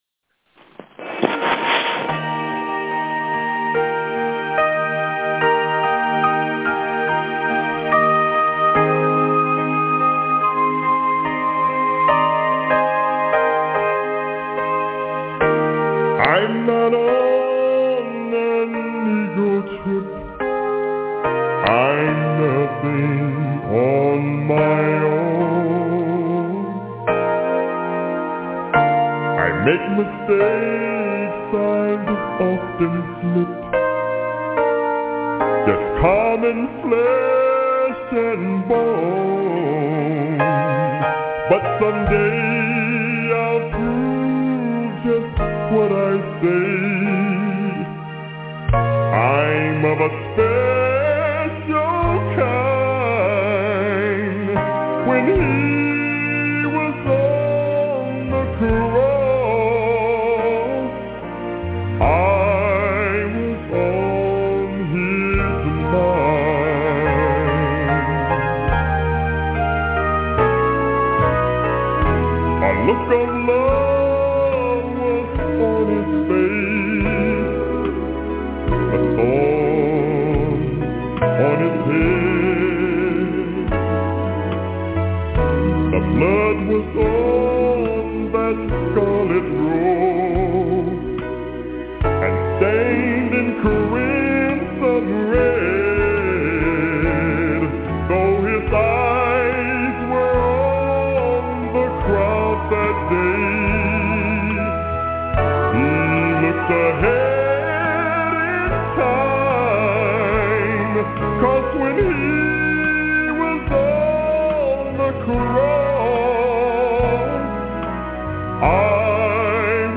This sermon shows the certainty of the pre-millennial kingdom, and our need to be washed and be readied in the robe of Y'shuah's righteousness, that we may be hid in the day of His anger.